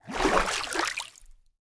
Minecraft Version Minecraft Version snapshot Latest Release | Latest Snapshot snapshot / assets / minecraft / sounds / mob / dolphin / jump3.ogg Compare With Compare With Latest Release | Latest Snapshot
jump3.ogg